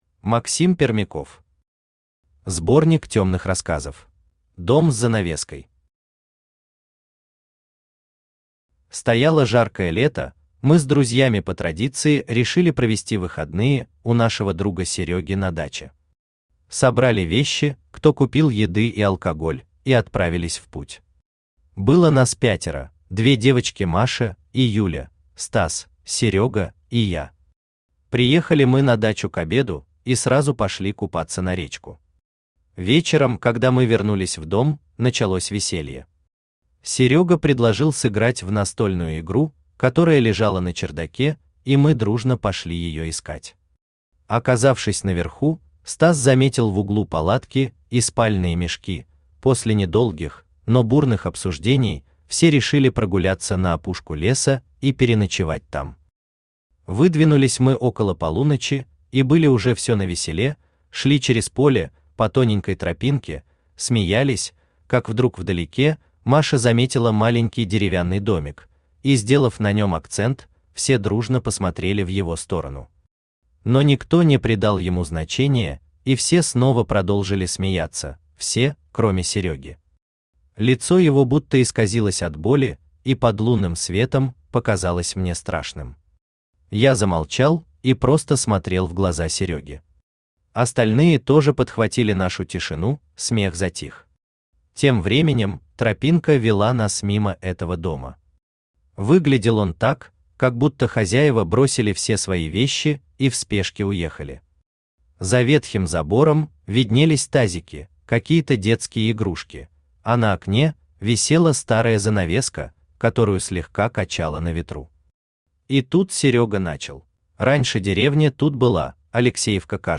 Аудиокнига Сборник тёмных рассказов | Библиотека аудиокниг
Aудиокнига Сборник тёмных рассказов Автор Максим Пермяков Читает аудиокнигу Авточтец ЛитРес.